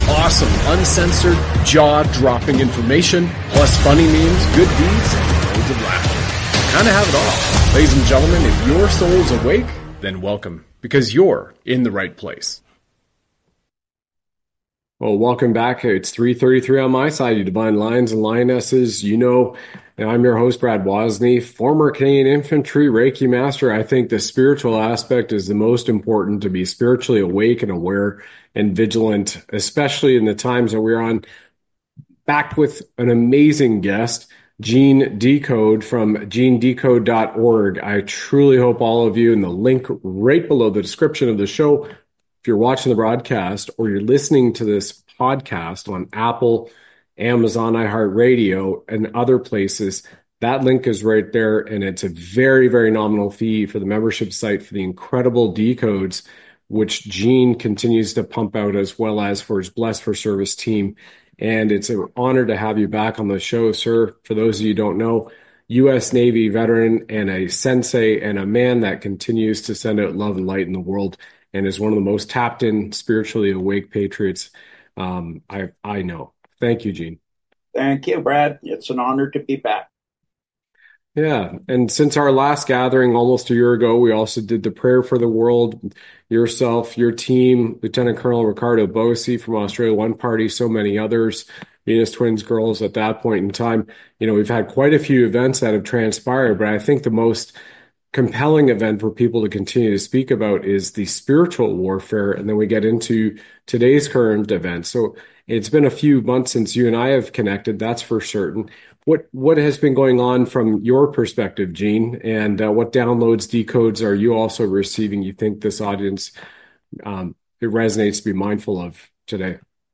Live Shows